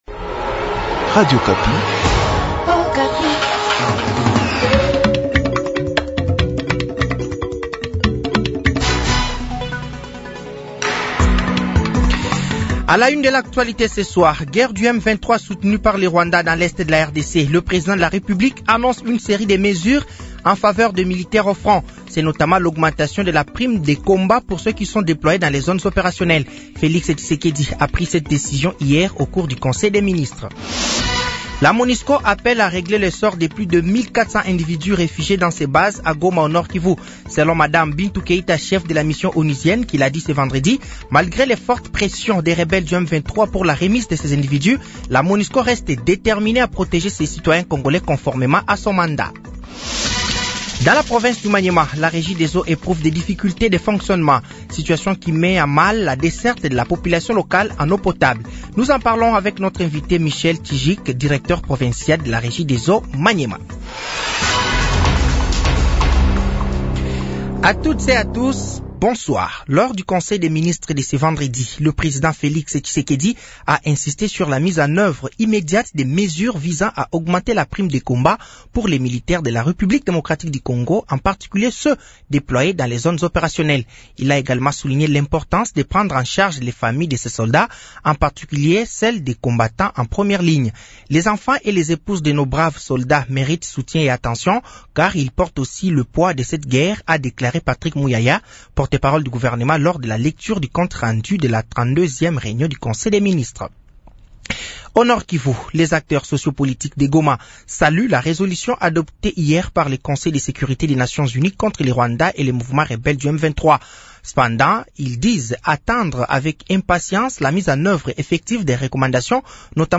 Journal français de 18h de ce samedi 22 février 2025